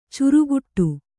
♪ curuguṭṭu